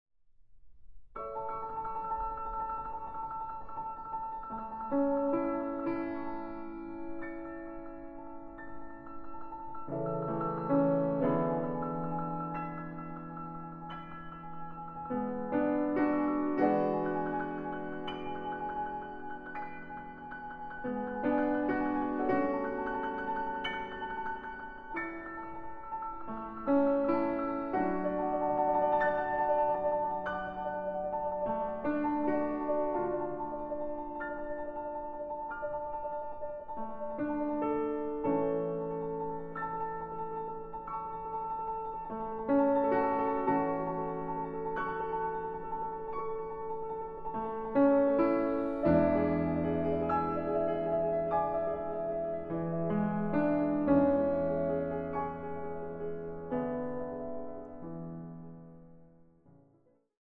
Recording: Mendelssohn-Saal, Gewandhaus Leipzig, 2024